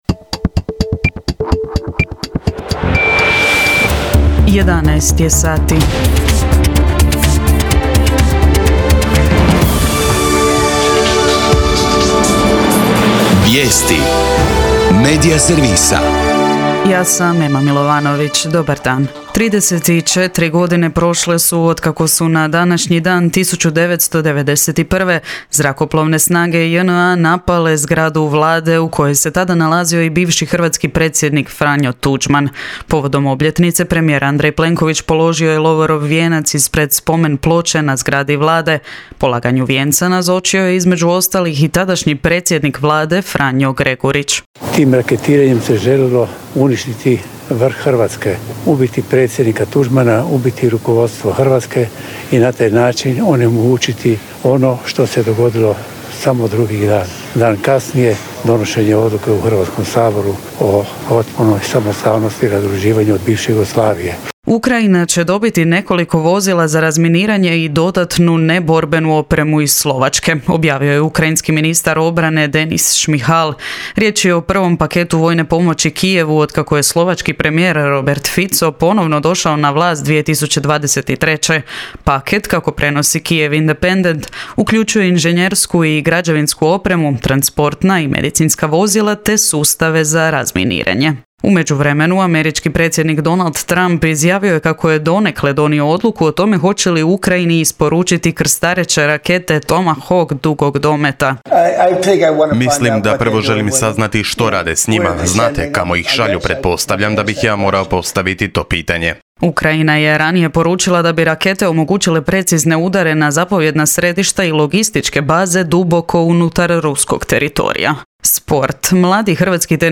VIJESTI U 11